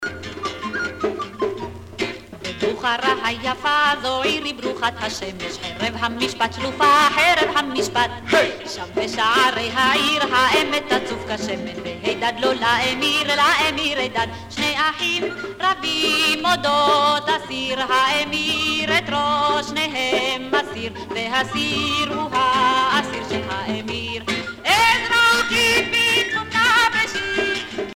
danse : hora (Israël)